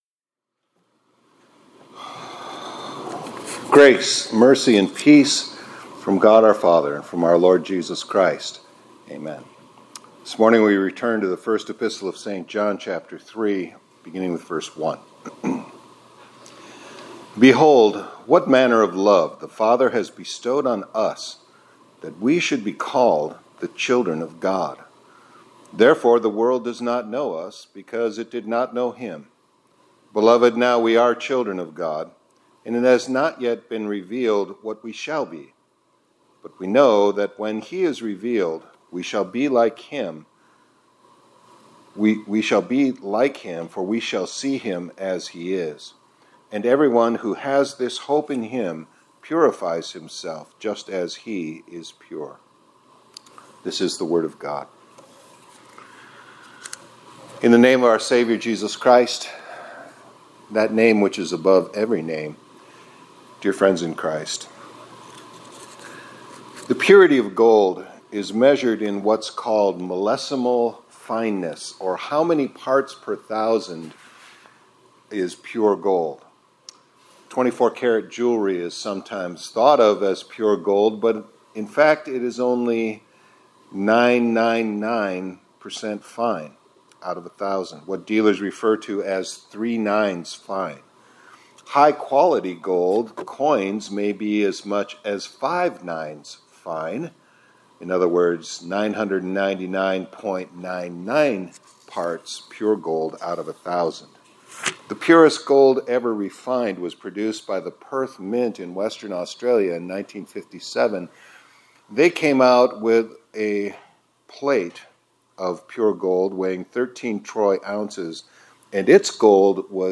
2025-02-18 ILC Chapel — “CHILD OF GOD” — A Name That Reveals How Pure a Path God Sets Us